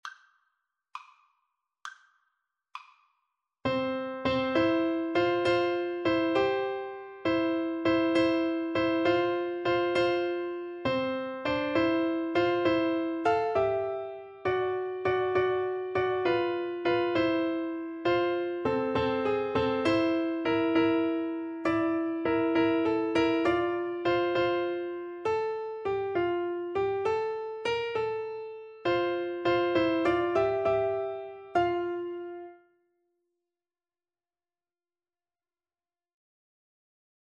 Free Sheet music for Piano Four Hands (Piano Duet)
F major (Sounding Pitch) (View more F major Music for Piano Duet )
6/8 (View more 6/8 Music)
Christmas (View more Christmas Piano Duet Music)